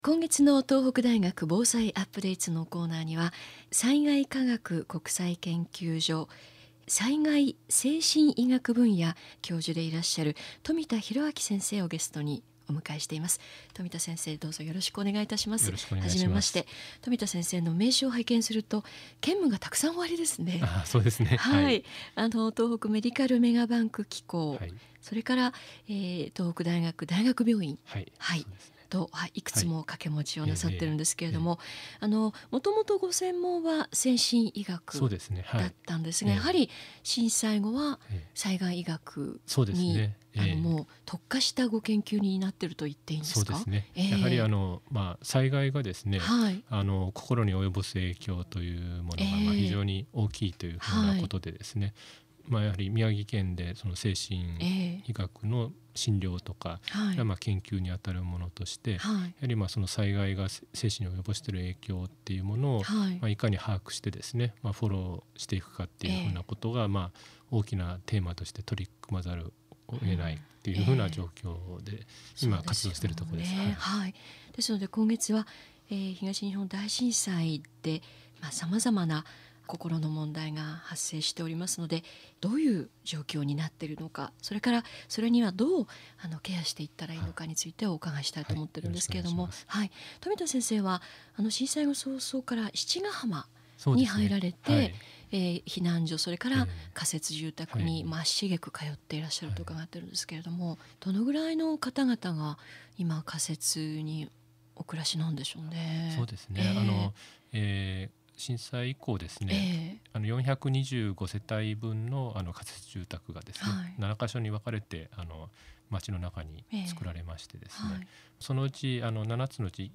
Date FM「東北大学防災UPDATES!」に出演しました。（2013年10月6日・20日）